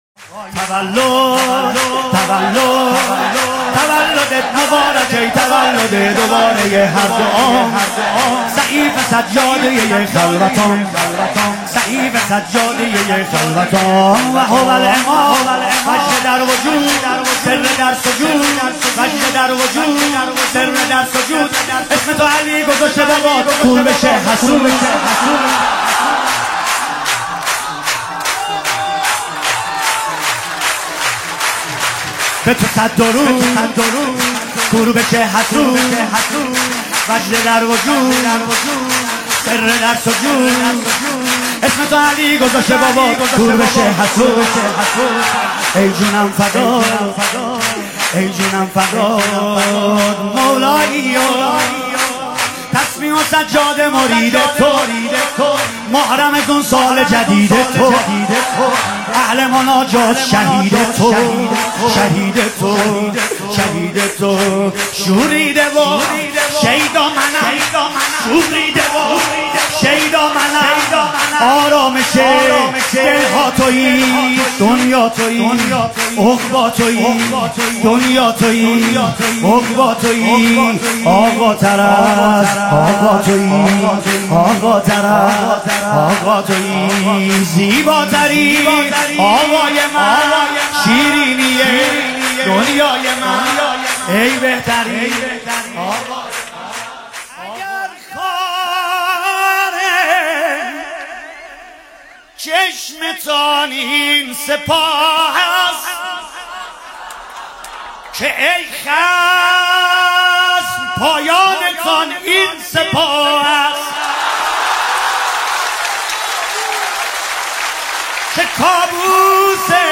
مولودی بسیار زیبا و خوش طنین